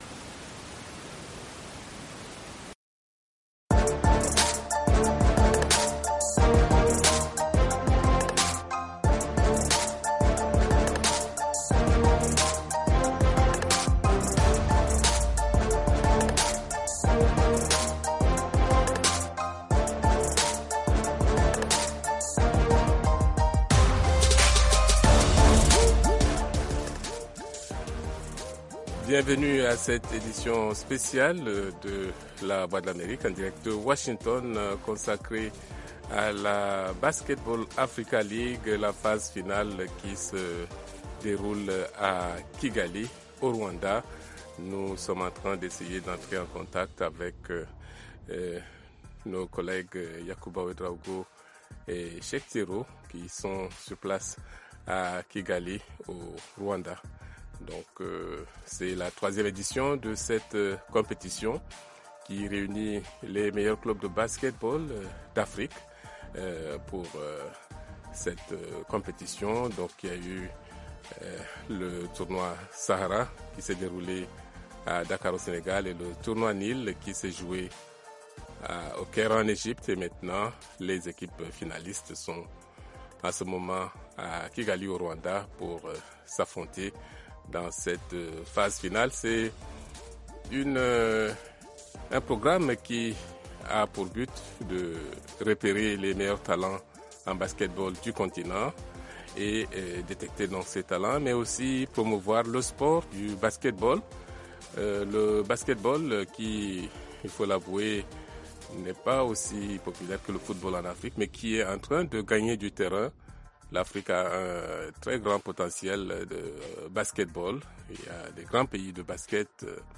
Basketball Africa League: en direct de Kigali